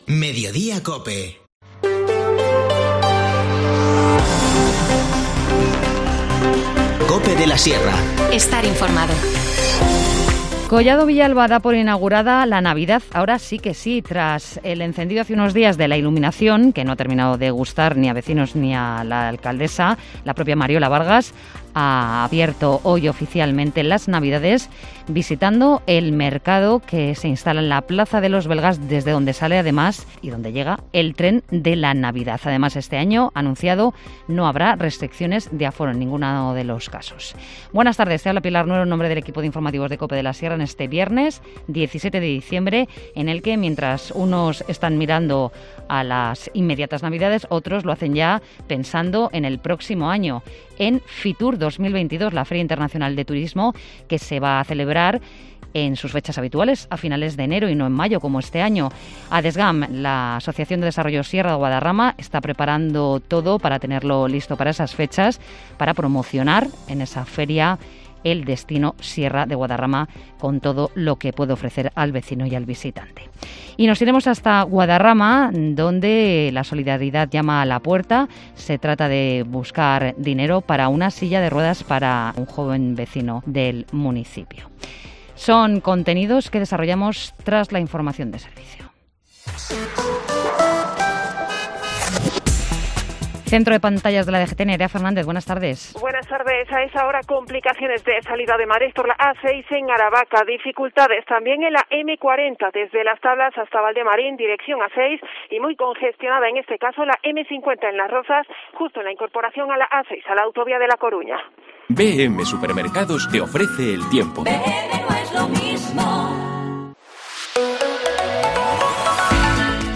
INFORMACIÓN LOCAL
Informativo Mediodía 17 diciembre